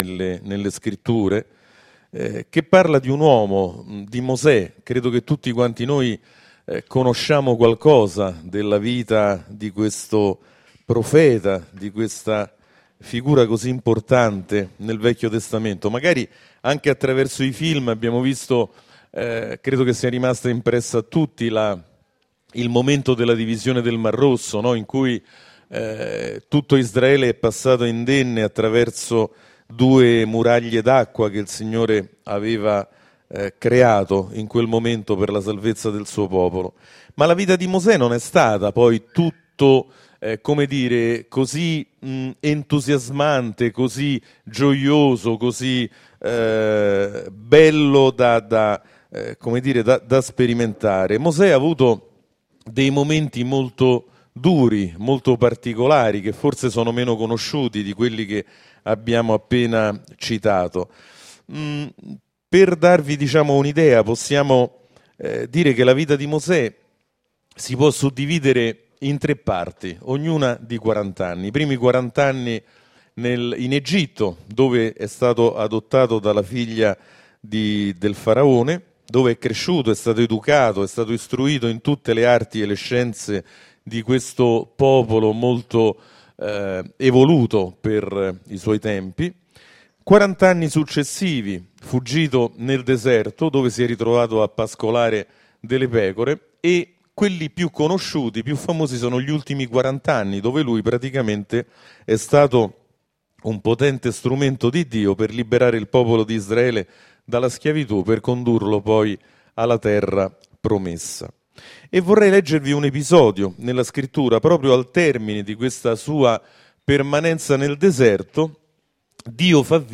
Sermoni della domenica